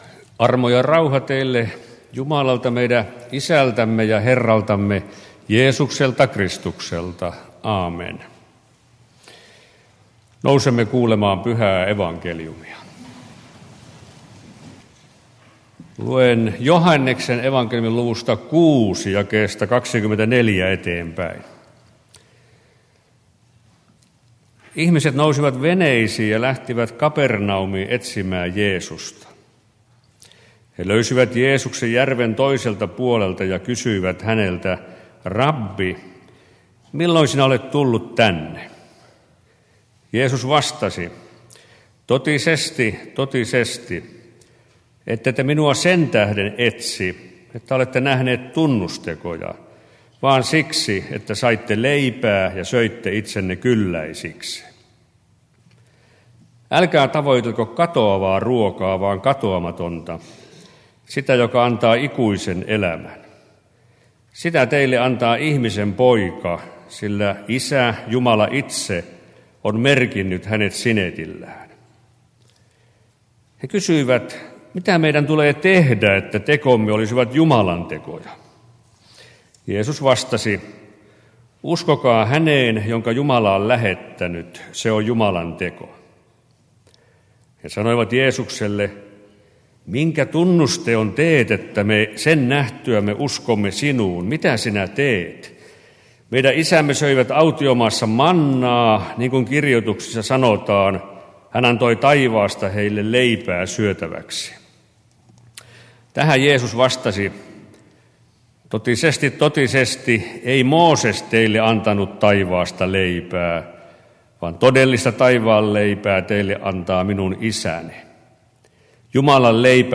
4. paastonajan sunnuntai Joh. 6:24-35 Sley:n E-P :n Talvijuhla Nurmossa